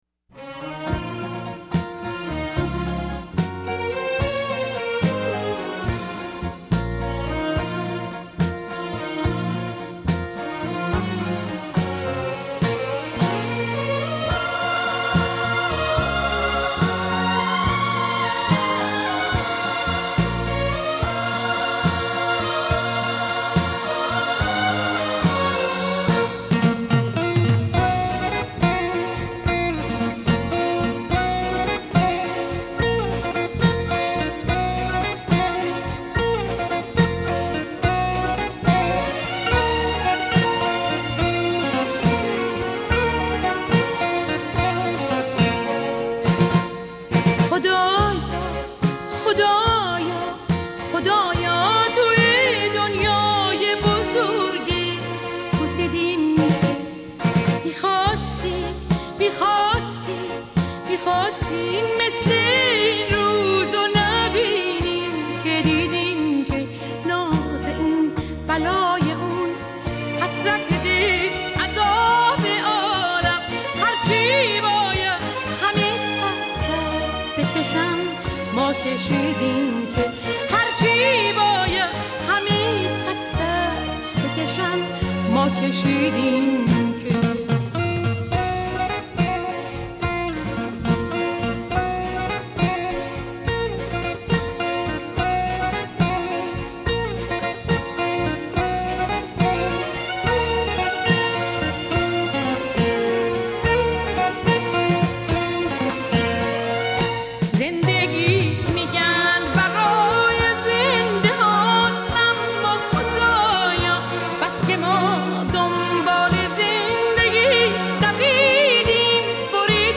موسیقی اصیل ایرانی